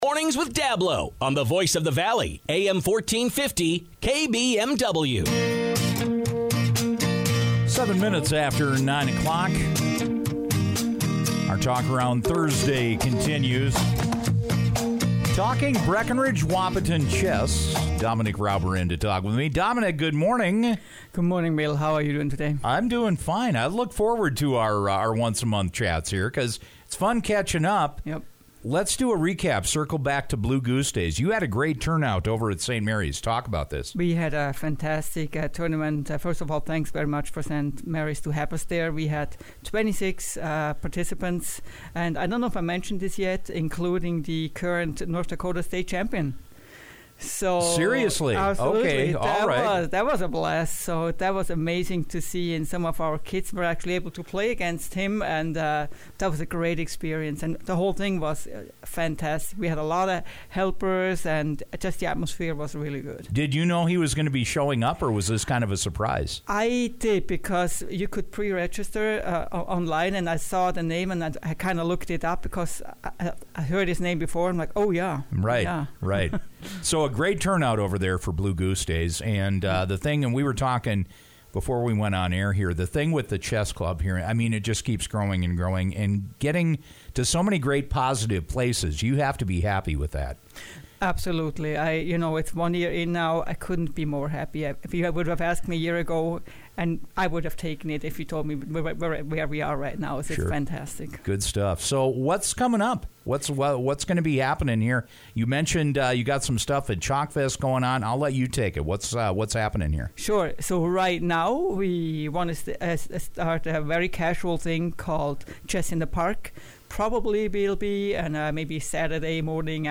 dropped by the KBMW Morning Show today to recap the event and talk about upcoming chess events for the club.